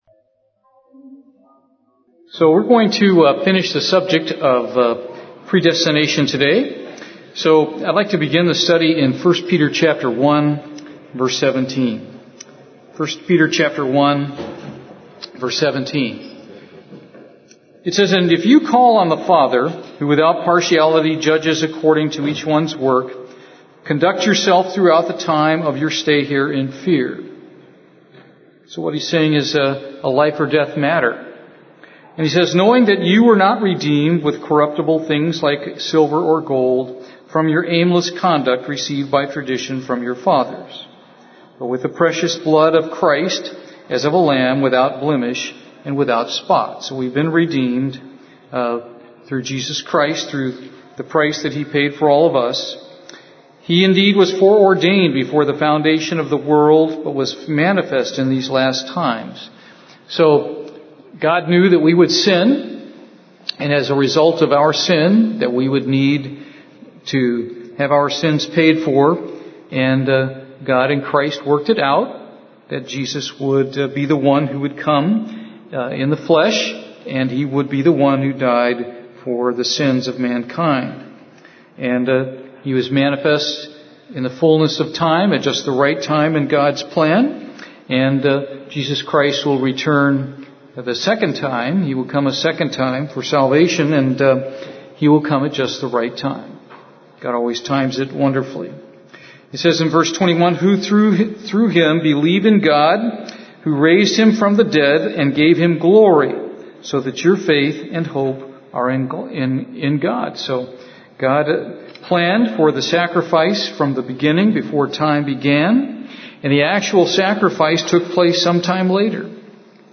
Given in Houston, TX
Bible study